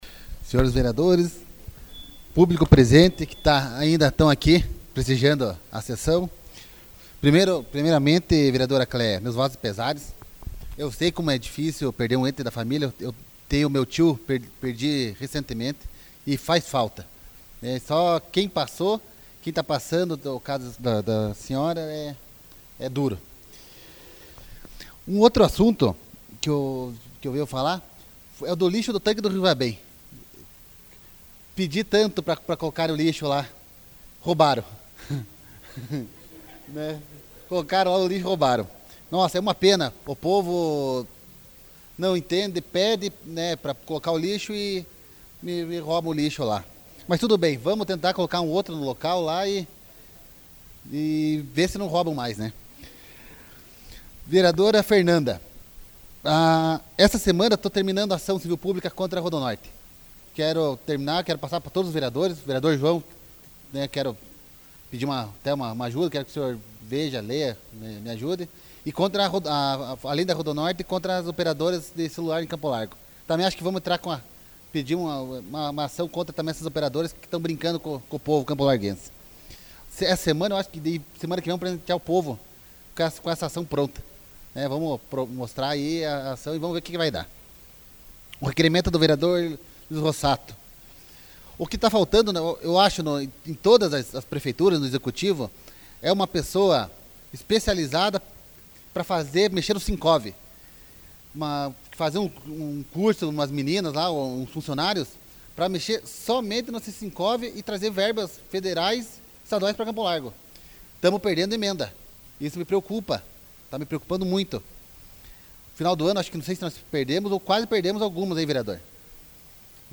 Explicação pessoal AVULSO 25/02/2014 Marcio Beraldo